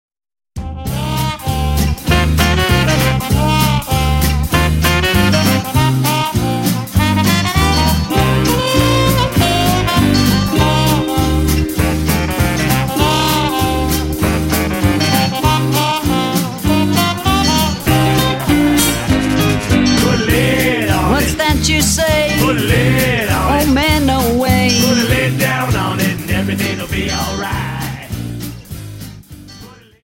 Dance: Quickstep 50 Song